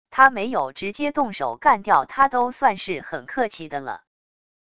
他没有直接动手干掉他都算是很客气的了wav音频生成系统WAV Audio Player